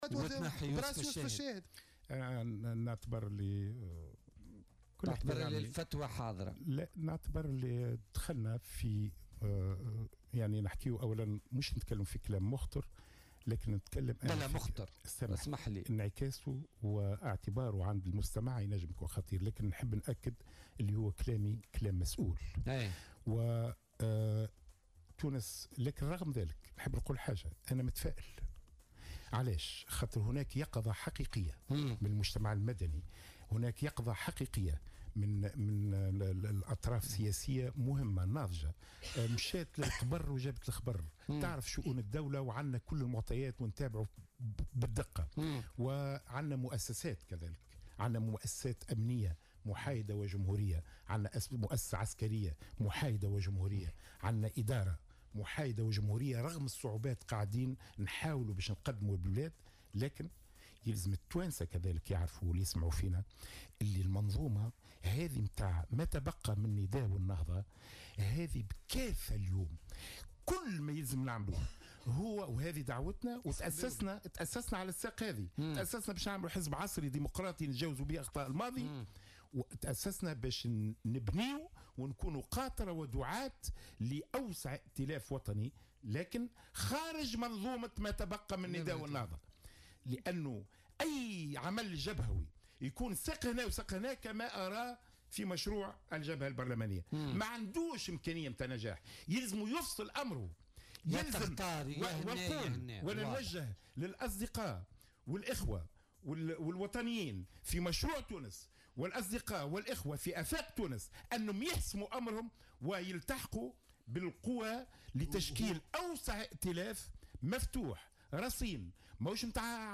أكد القيادي في حزب تونس أولا خميس كسيلة ضيف بولتيكا اليوم الإثنين 13 نوفمبر 2017 أن فتوى اسقاط حكومة يوسف الشاهد تطبخ في الكواليس .